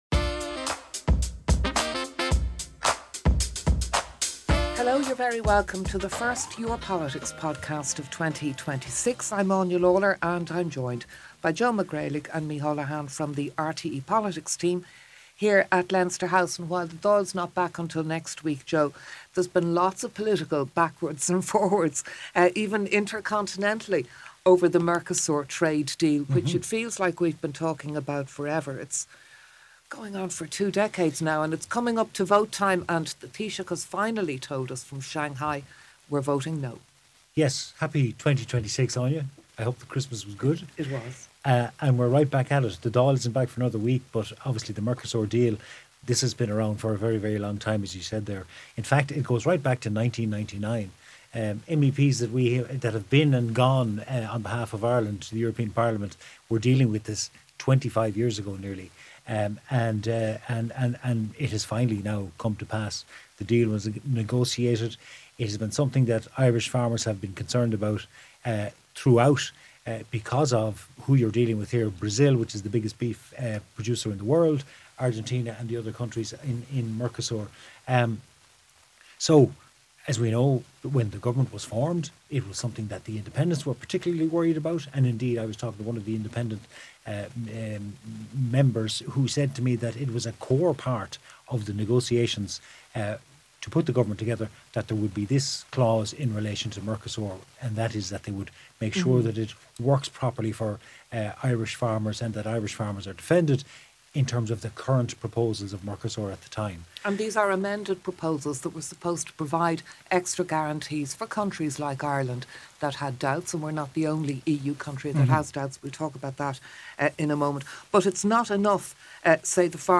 The RTÉ team at Leinster House and guests have an informal discussion about what has been happening in the world of Irish politics.